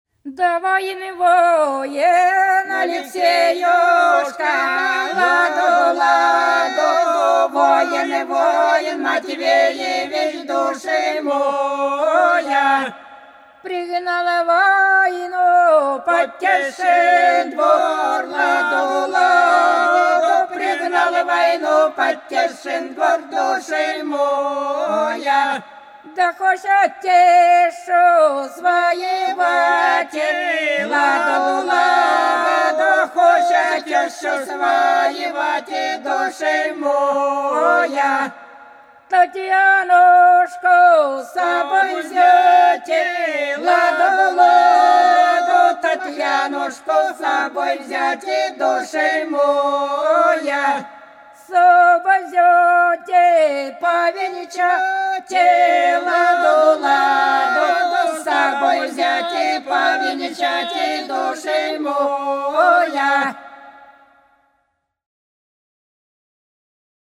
По-над садом, садом дорожка лежала Воин, воин, Алексеюшка - свадебная (с.Плёхово, Курская область)
12_Воин,_воин,_Алексеюшка_(свадебная).mp3